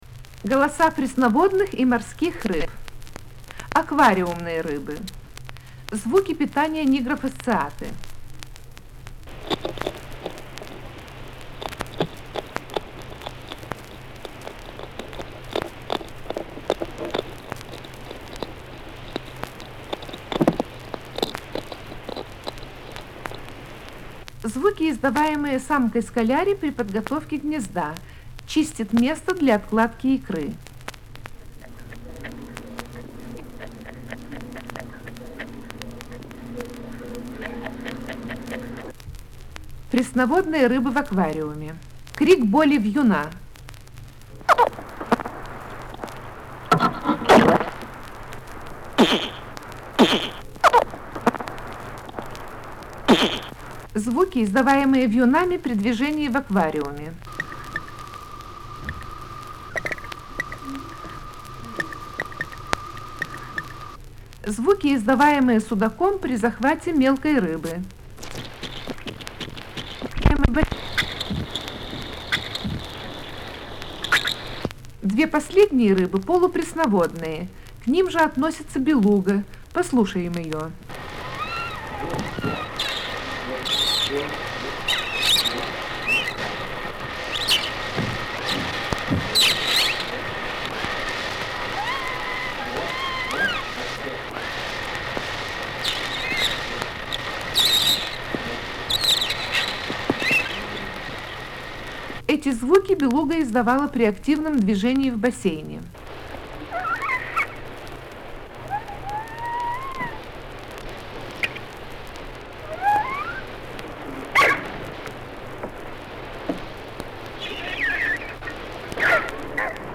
Голоса подводного мира звуки рыб